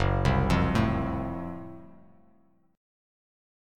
F#mM7b5 chord